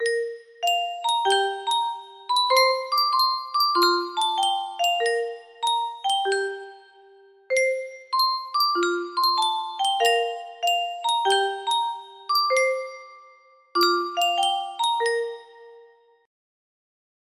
music boxes